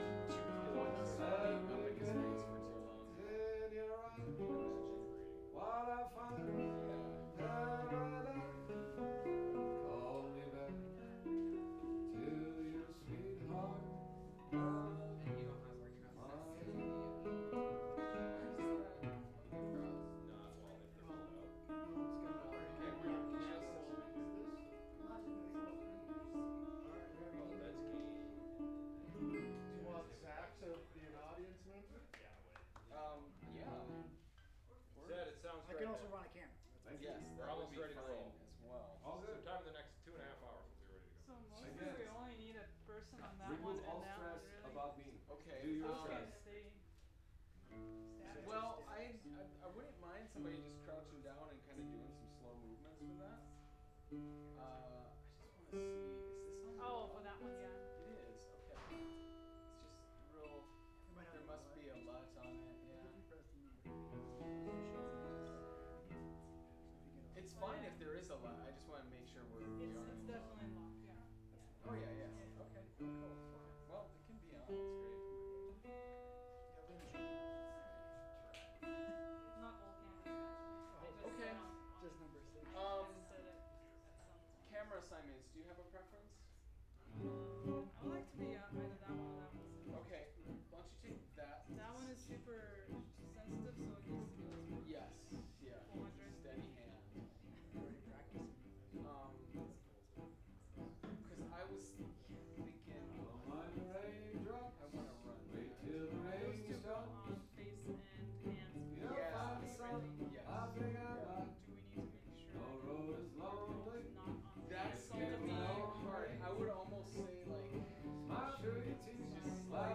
VP88_01 L.wav